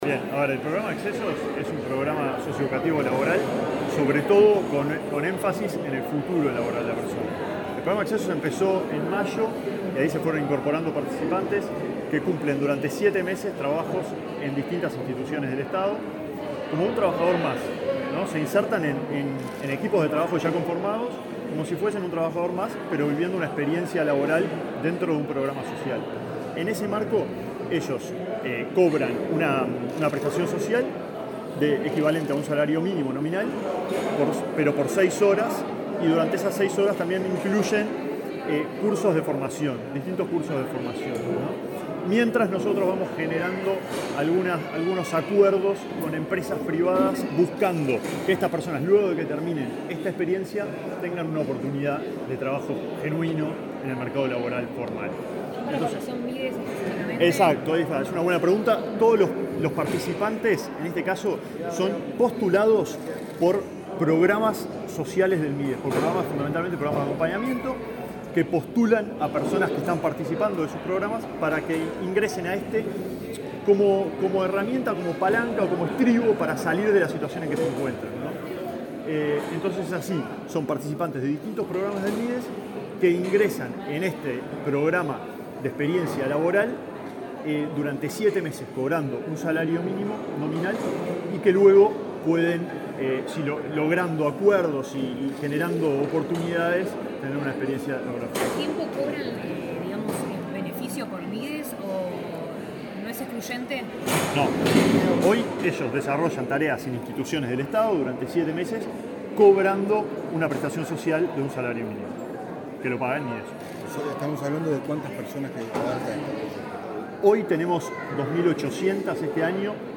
Declaraciones del director de Gestión Territorial del Mides, Alejandro Sierra